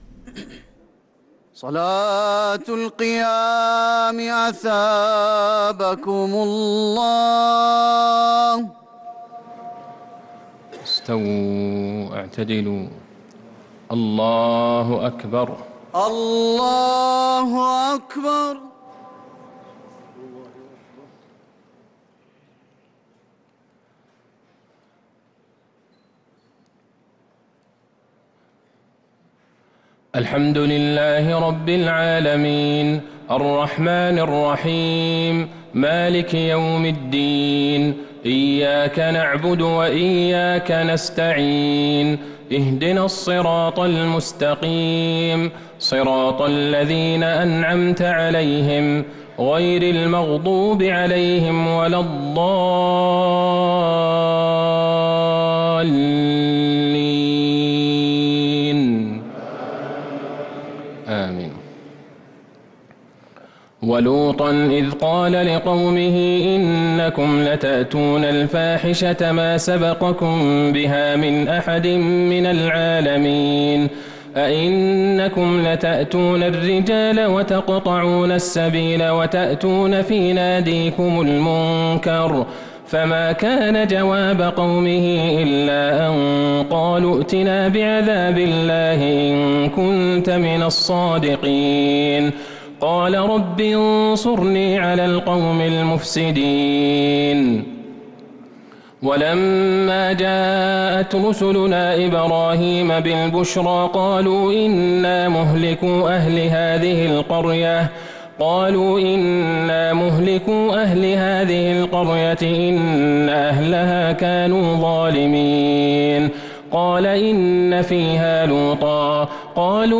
صلاة التراويح ليلة 24 رمضان 1443 للقارئ عبدالله البعيجان - الثلاث التسليمات الأولى صلاة التراويح